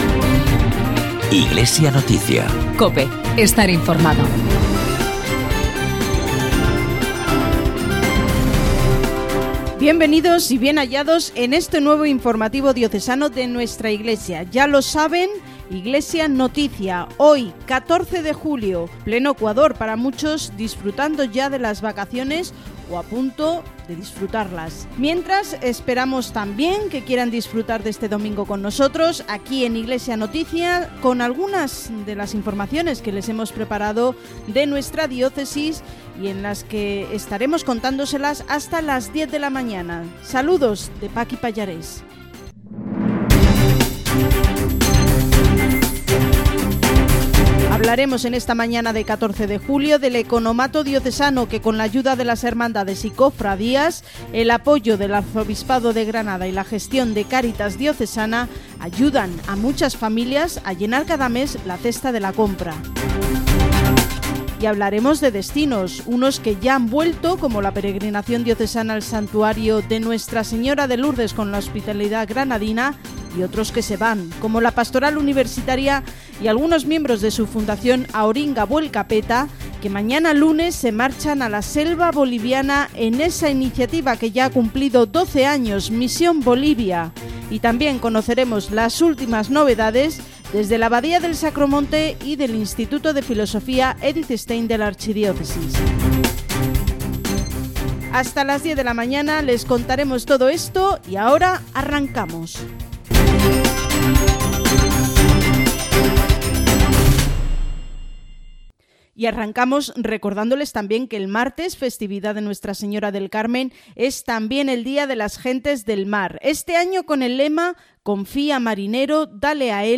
Emitido en COPE Granada, el domingo 14 de julio de 2019.